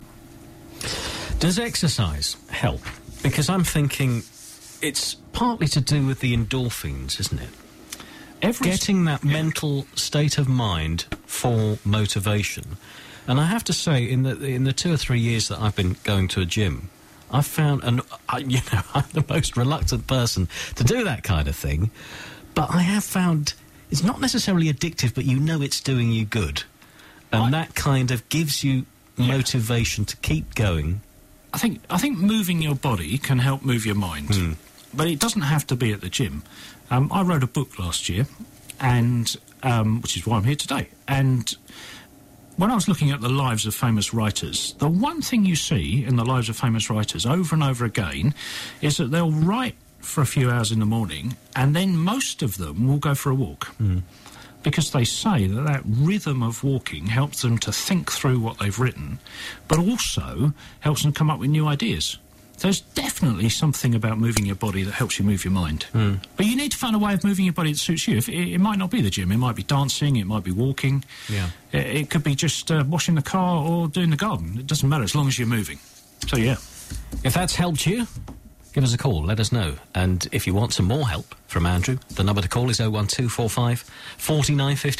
All the best bits from my Sound Advice slot on BBC Essex 24 Jan 2012.